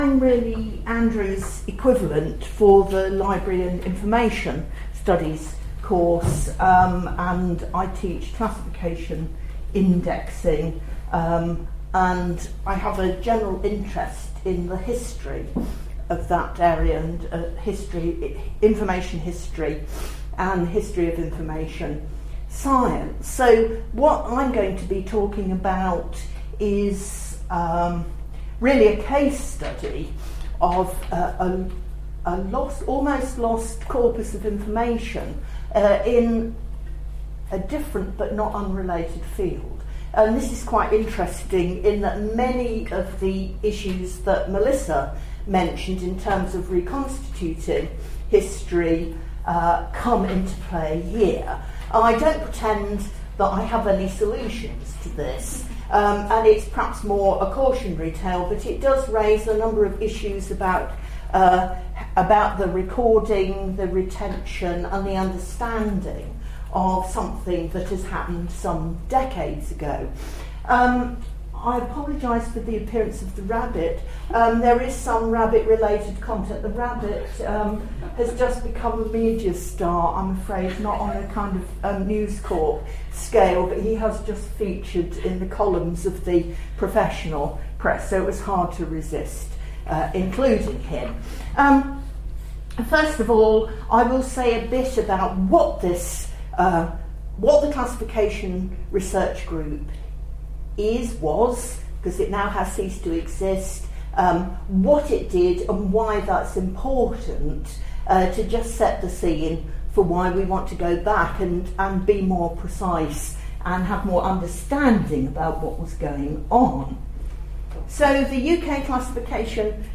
Presentation
at the Hidden Histories Symposium, September 2011, UCL.